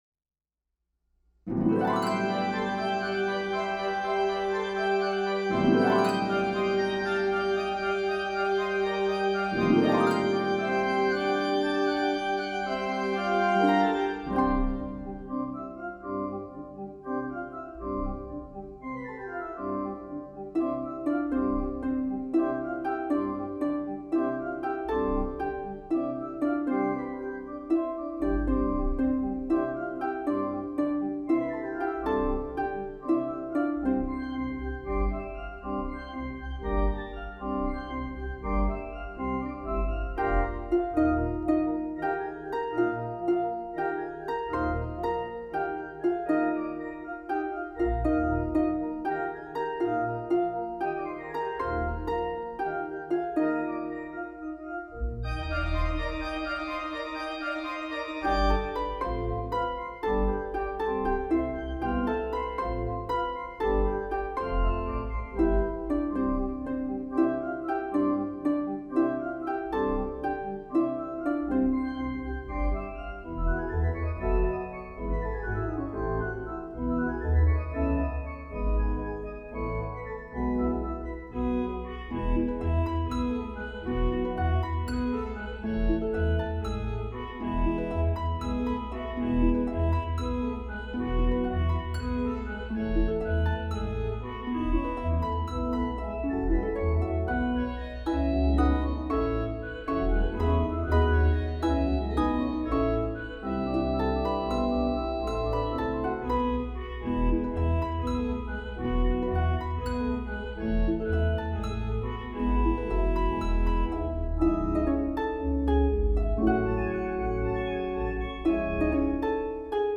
is an energetic piece for pedal harp and organ